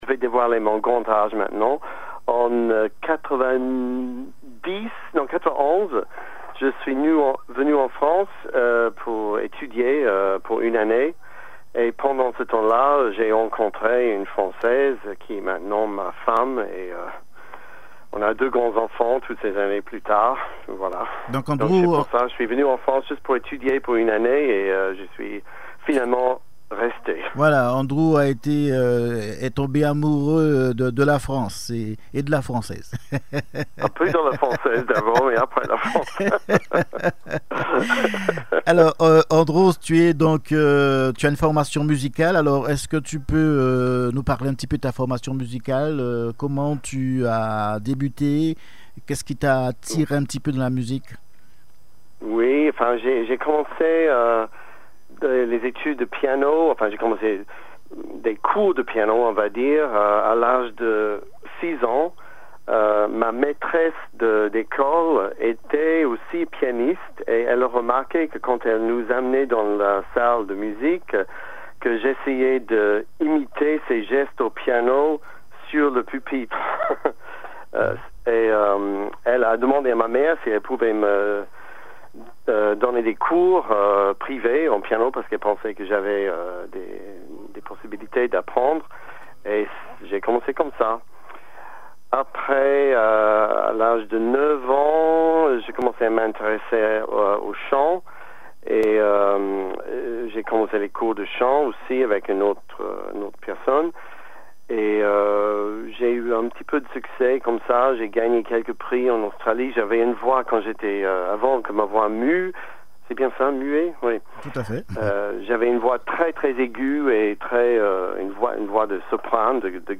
Listen to the interview (in French) on Radio Vie Meilleure (Better Life) in Guadeloupe :
interview.mp3